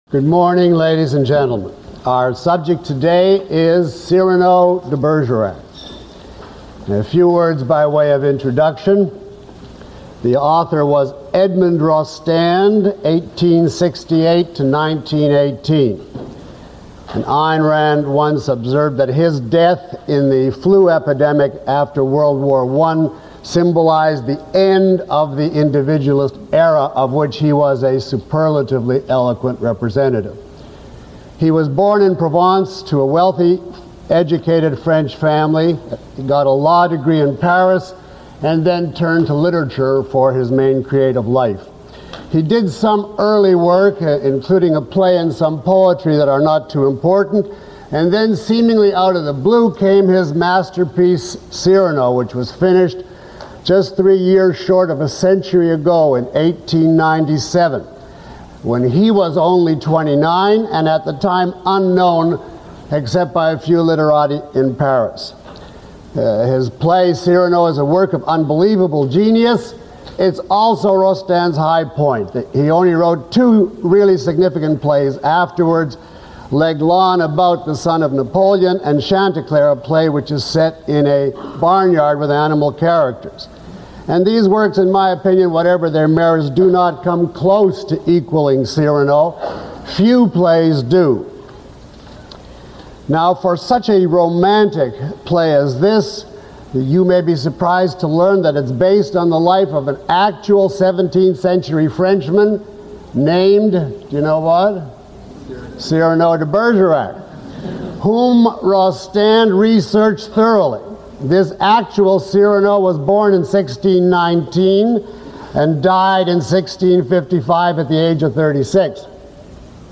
This lecture is a discussion of Cyrano de Bergerac by Edmond Rostand.
Below is a list of questions from the audience taken from this lecture, along with (approximate) time stamps.